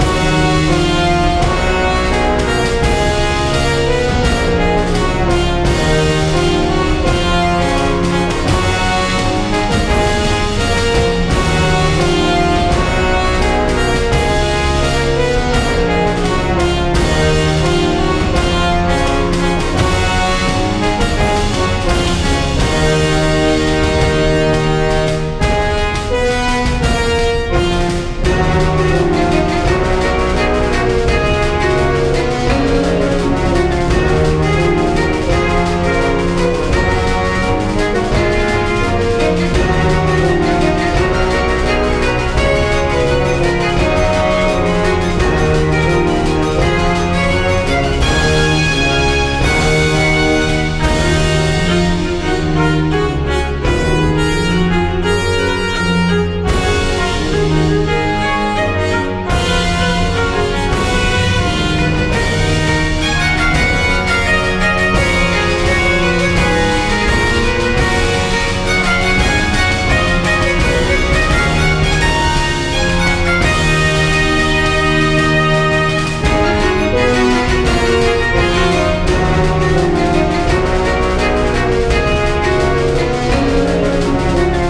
RPGやアクションゲームなどの通常戦闘シーンを想定して制作した、テンポ感のある戦闘用BGMです。
ジャンル： ゲーム音楽／バトルBGM／ファンタジー／アクション
雰囲気： テンポ感／疾走感／緊張感／軽快／前向き